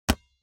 دانلود آهنگ تصادف 46 از افکت صوتی حمل و نقل
دانلود صدای تصادف 46 از ساعد نیوز با لینک مستقیم و کیفیت بالا
جلوه های صوتی